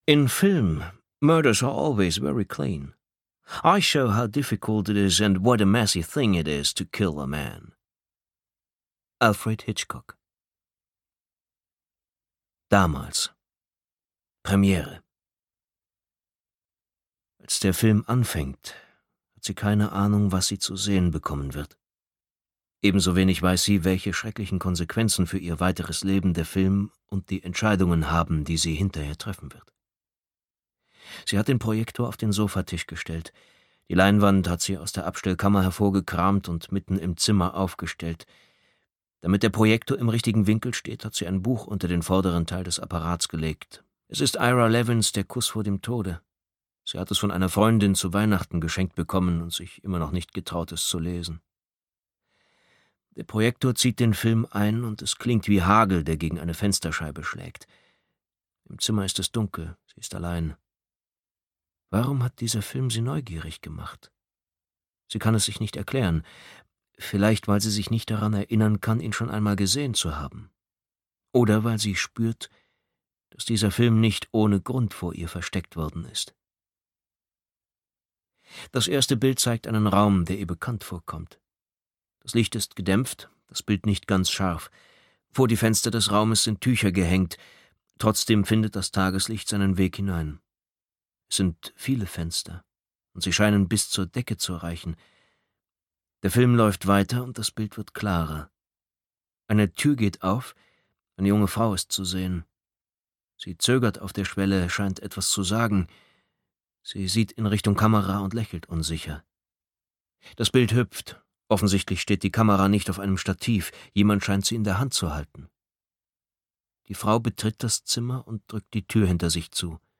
Sterntaler (DE) audiokniha
Ukázka z knihy